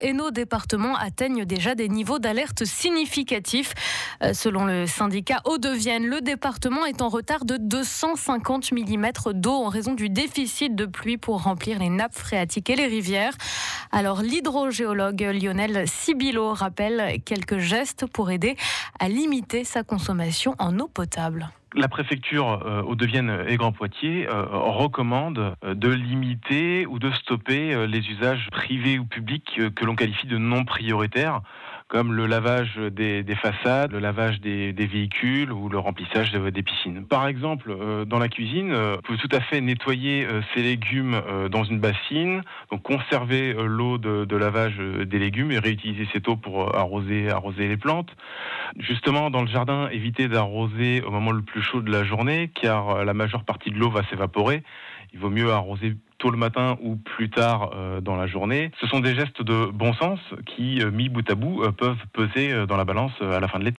Ecoutez sa 1ère intervention radio (1mn) sur l’état de vigilance sécheresse annoncée en début de semaine dans la Vienne.